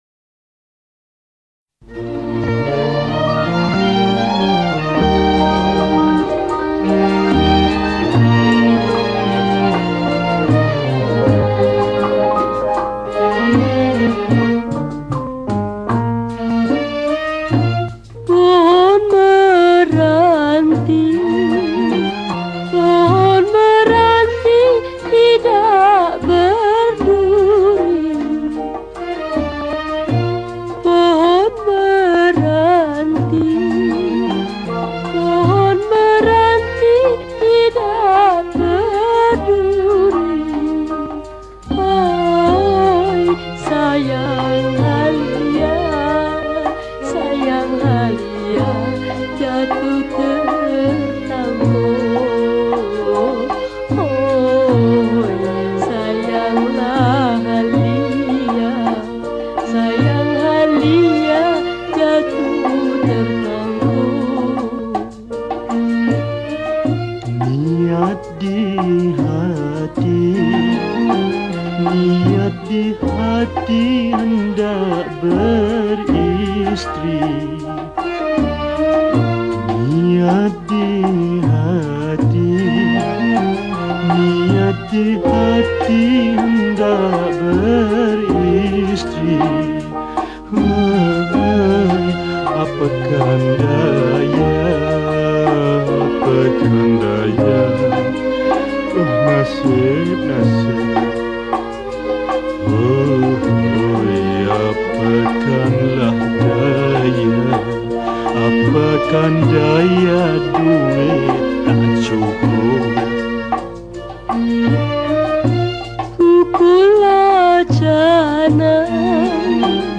Malay Song